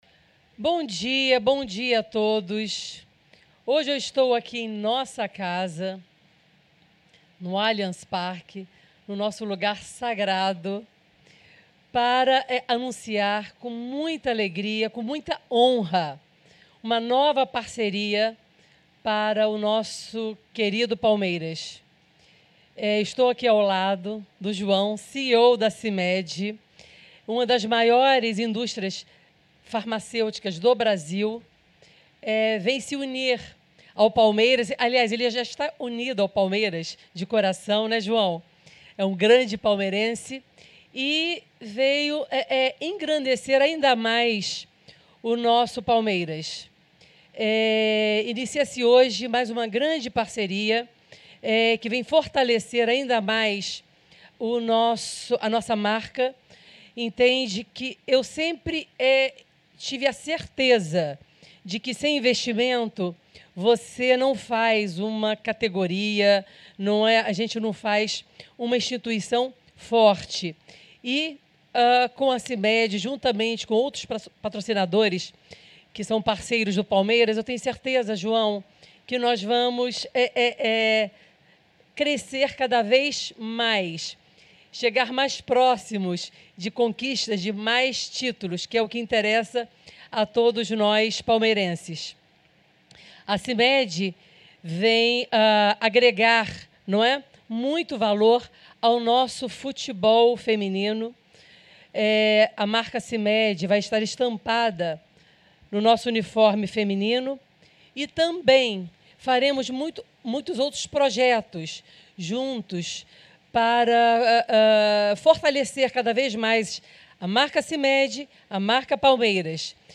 AUDIO-COLETIVA-CIMED.mp3